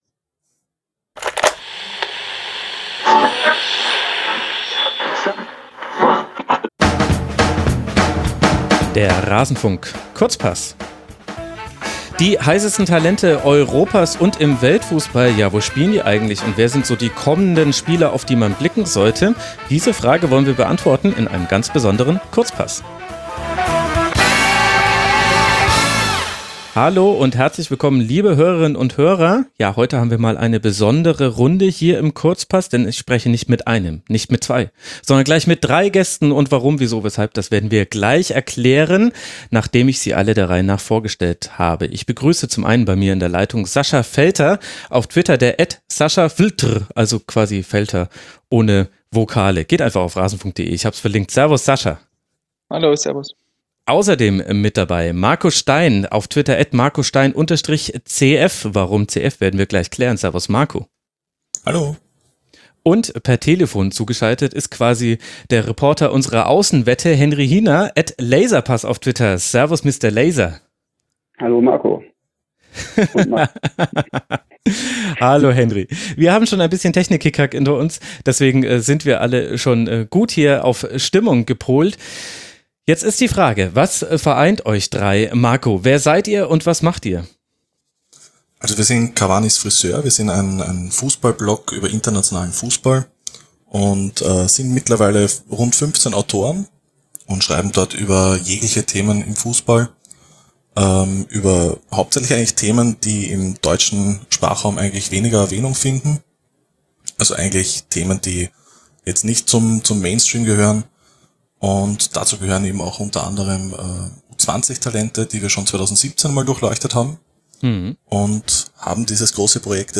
Wer gehört zu den besten Spielern unter 20 Jahren? Aus welchem Land kommen die besten Talente? Ein Gespräch über die Zukunft des Fußballs.